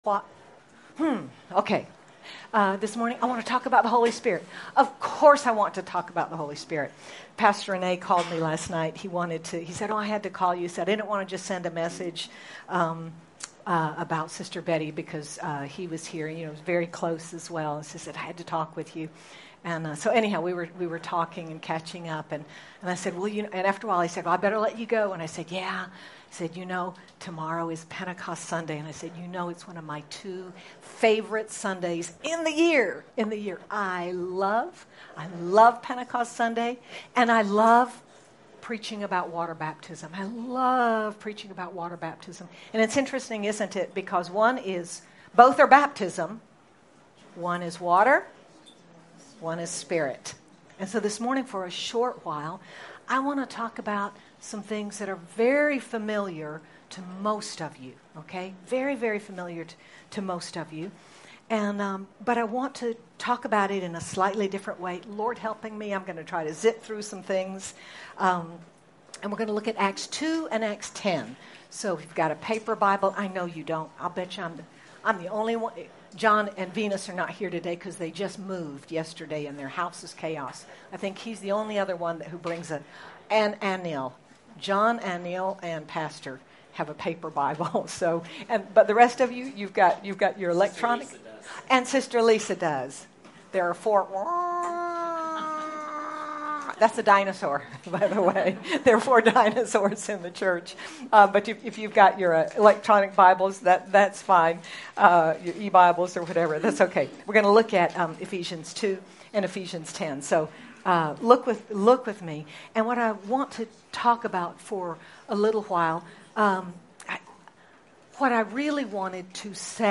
Jun 09, 2025 When God the Spirit Moves MP3 SUBSCRIBE on iTunes(Podcast) Notes Discussion In this Pentecost Sunday message, we look at how God the Spirit moved at Pentecost and at the home of Cornelius. How the Spirit moved then shows us what draws Him to work in our lives and situations today.